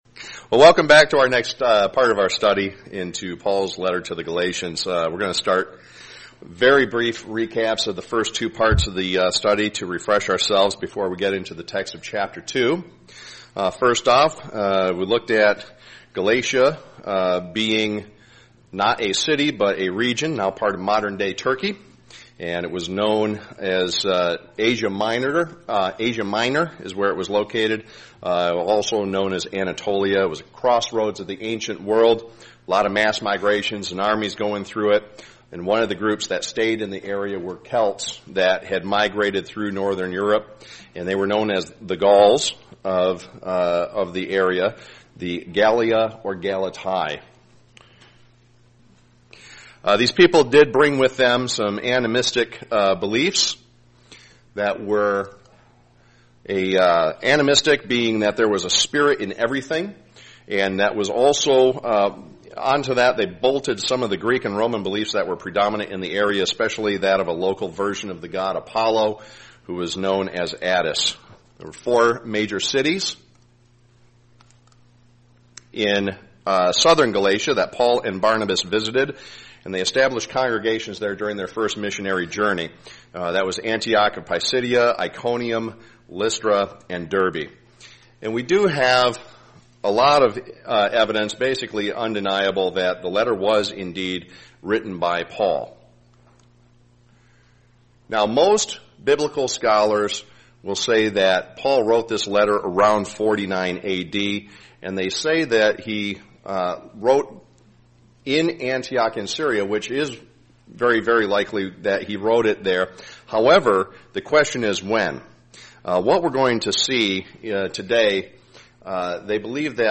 Galatians Bible Study, Part 3
Given in Flint, MI